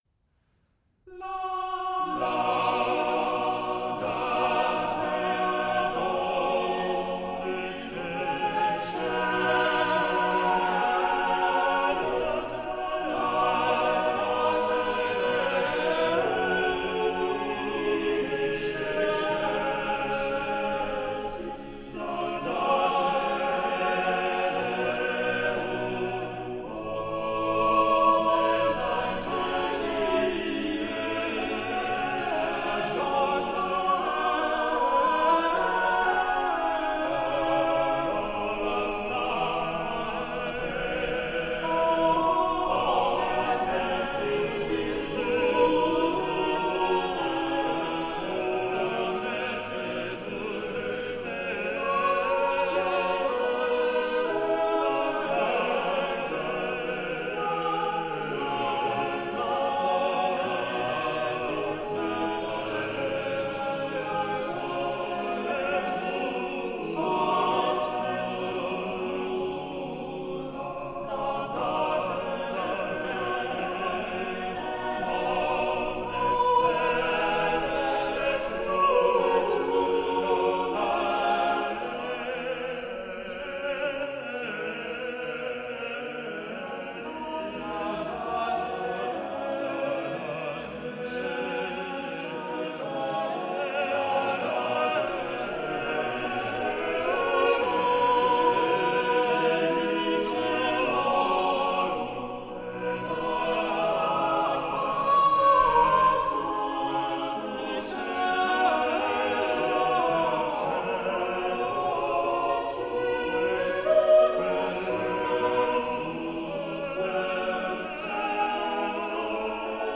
into a work full of vitality.